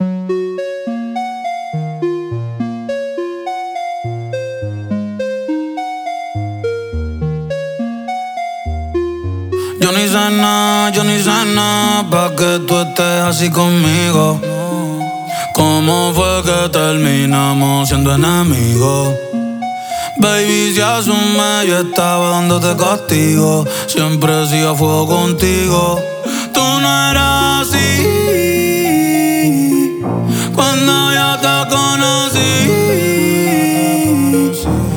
Скачать припев
Latin